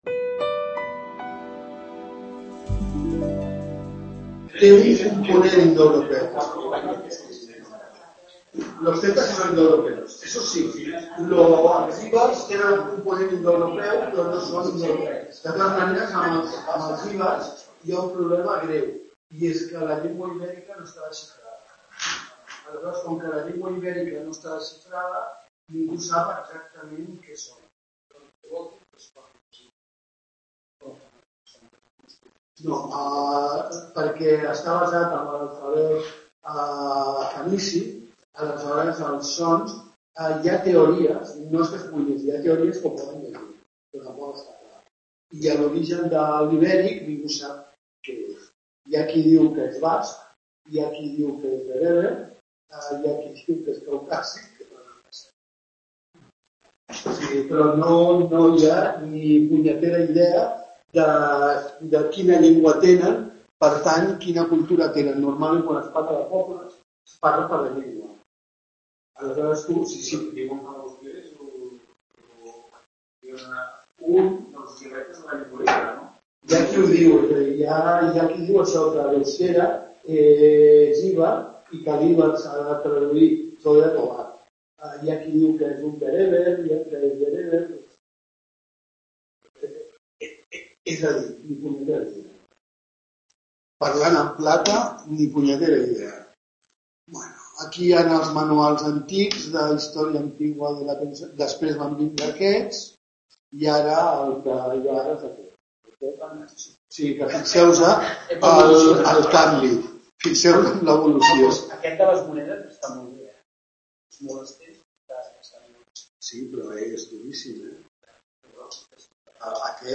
tutoria acerca de la historia antigua hispana